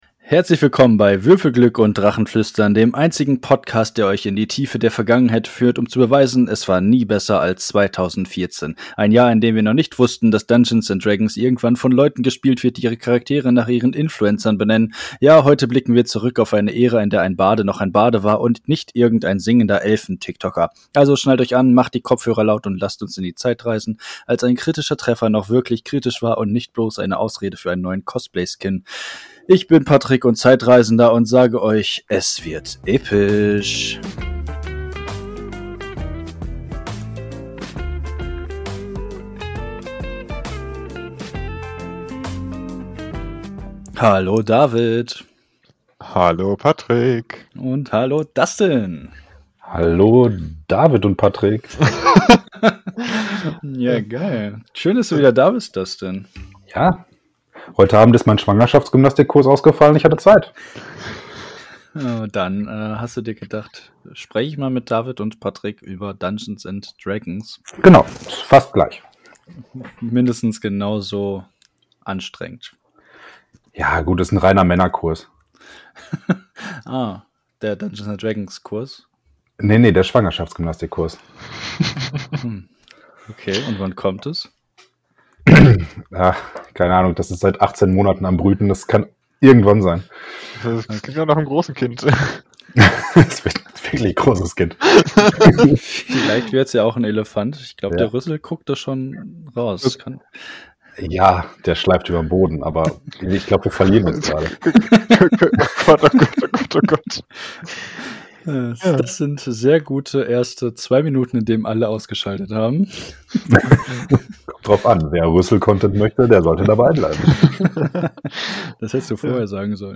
Wir schweifen in dieser Folge und in jeder zukünftigen, gerne ab.